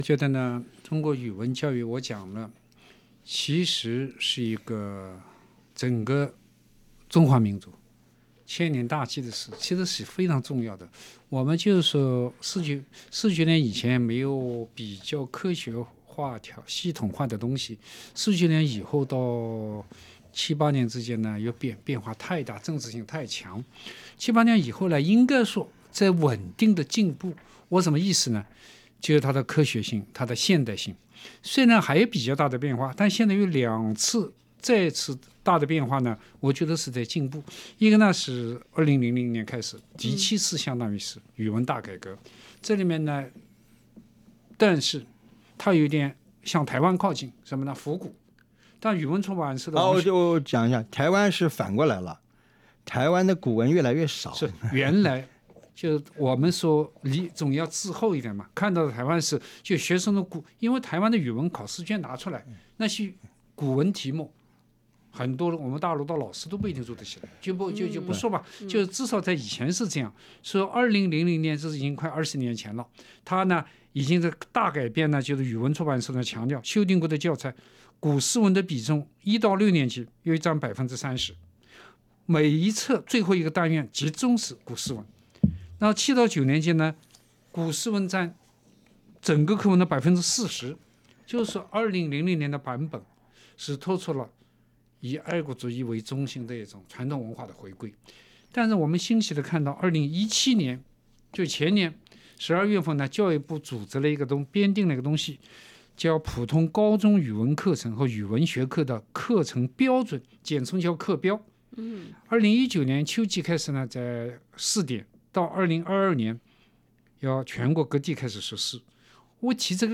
SBS电台《文化苦丁茶》每周五早上澳洲东部时间早上8:15播出，每周日早上8:15重播。